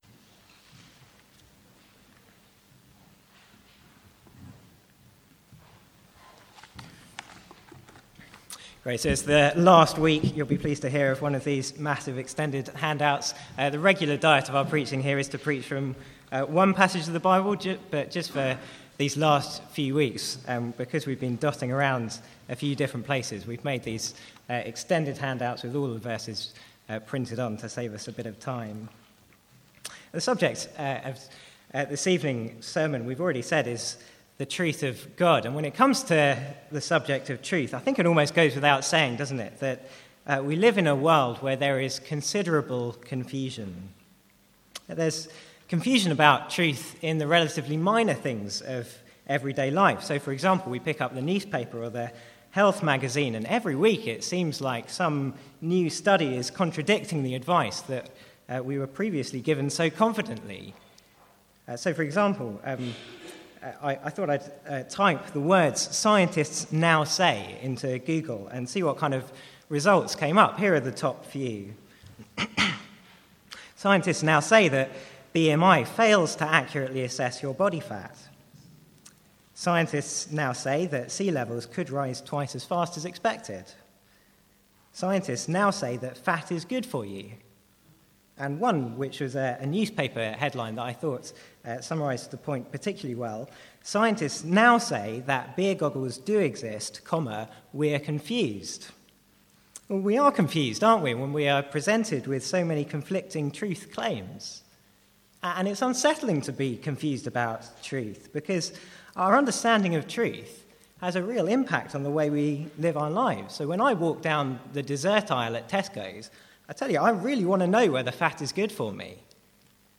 Sermons | St Andrews Free Church
From the Sunday evening series on Question 4 of the Westminster Shorter Catechism - "What is God?"